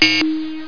beep1.mp3